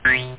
boing.mp3